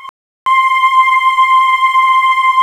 Index of /90_sSampleCDs/USB Soundscan vol.28 - Choir Acoustic & Synth [AKAI] 1CD/Partition C/10-HOOOOOO
HOOOOOO C5-L.wav